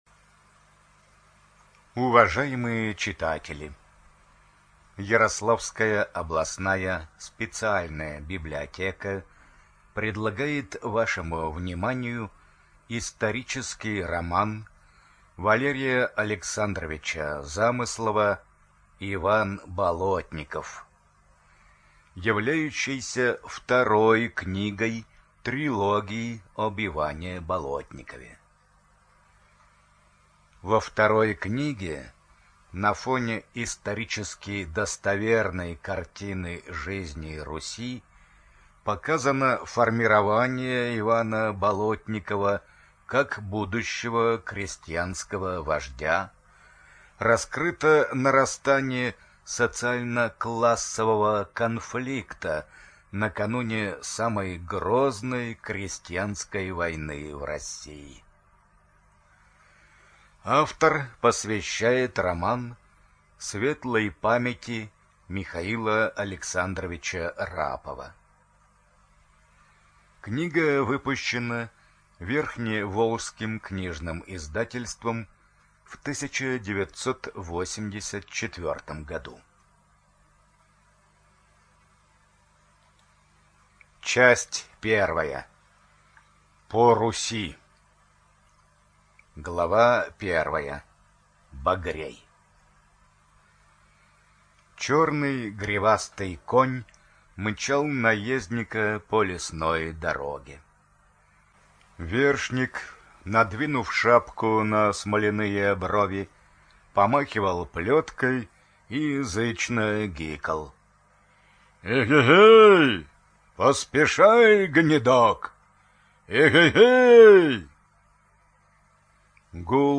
Студия звукозаписиЯрославская областная библиотека для слепых